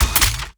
Plasma Rifle
GUNMech_Reload_10_SFRMS_SCIWPNS.wav